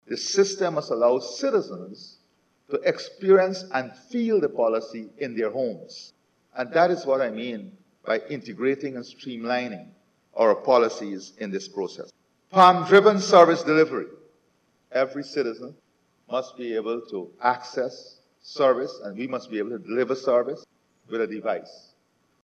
H.E Dr. Mohamed Irfaan Ali, President of the Co-operative Republic of Guyana